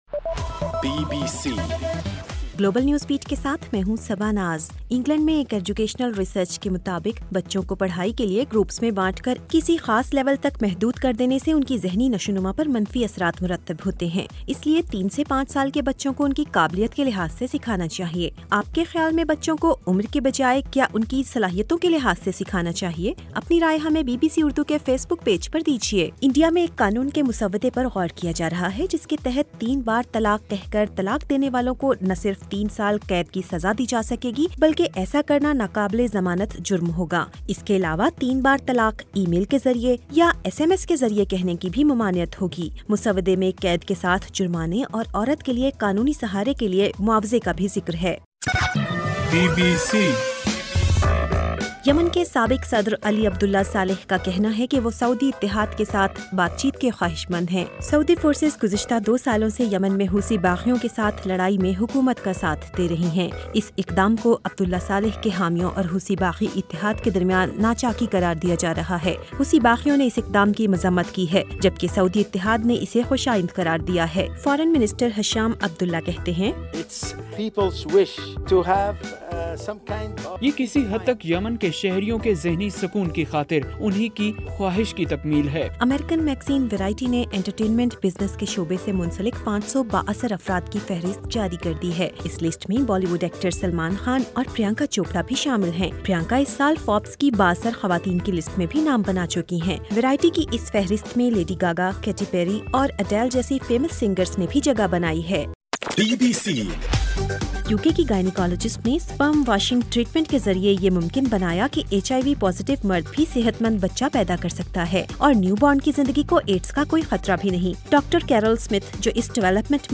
گلوبل نیوز بیٹ بُلیٹن اُردو زبان میں رات 8 بجے سے صبح 1 بجے تک ہر گھنٹےکے بعد اپنا اور آواز ایف ایم ریڈیو سٹیشن کے علاوہ ٹوئٹر، فیس بُک اور آڈیو بوم پر ضرور سنیے۔